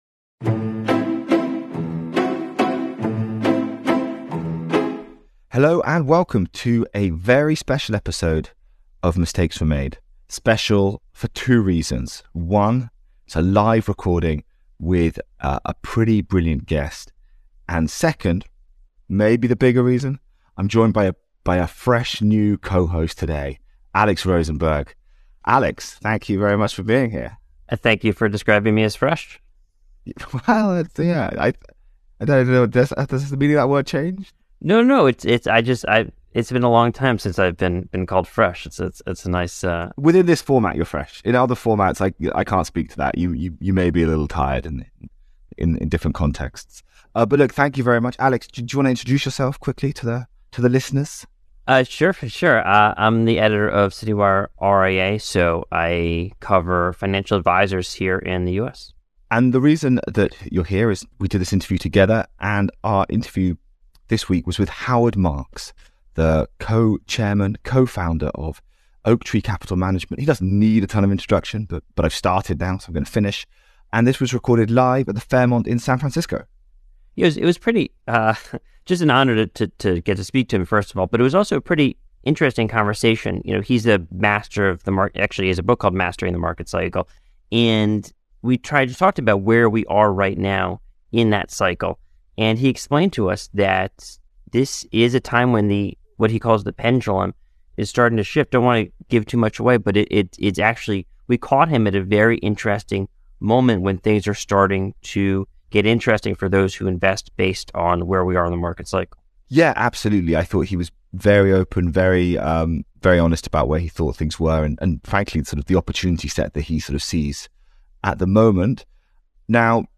Recorded live in San Francisco on November 17th.